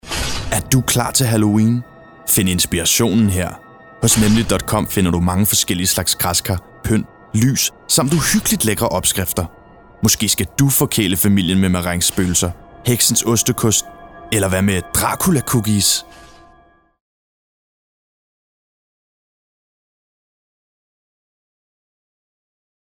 Mand
20-30 år
Reklame